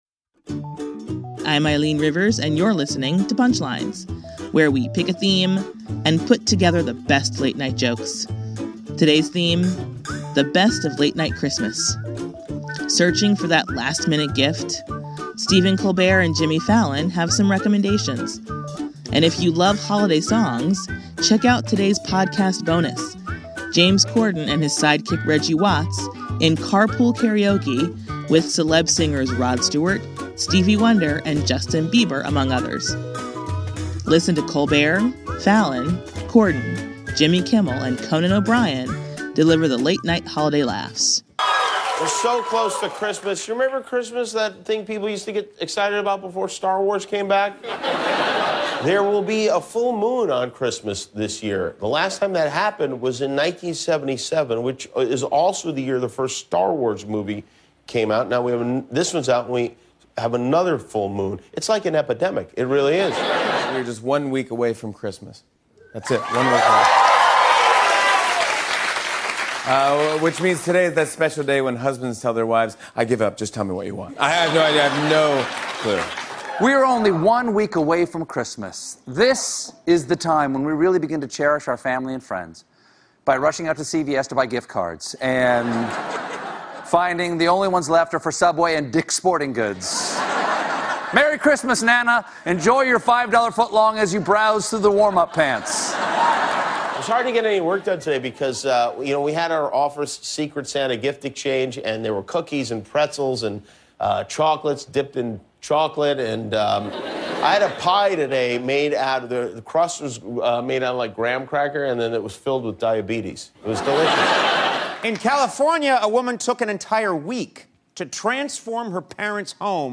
The comics take a look at traditions and work in some holiday music.